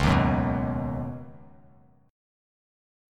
C#7sus2sus4 chord